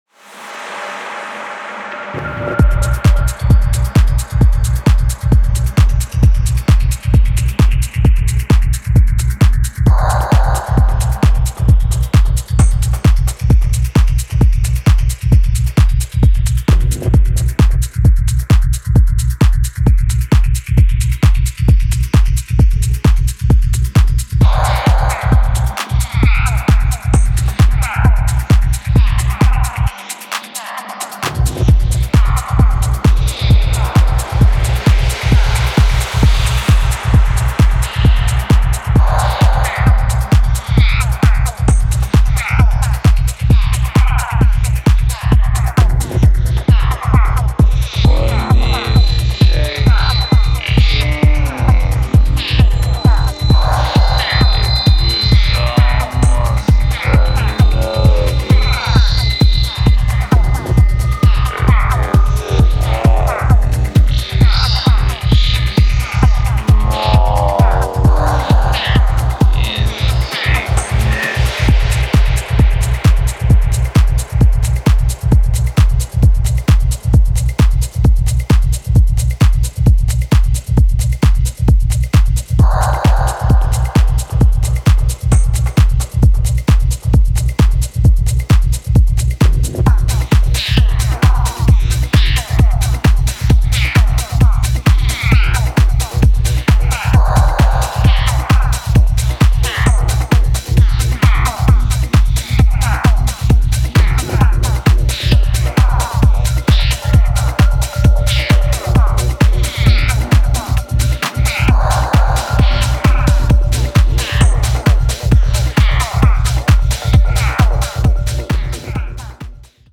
ダークに蠢くローエンドを基調に
フリーキーなヴォイスチョップがブッ飛んでる
タイトでテンション高いグルーヴが揃いました。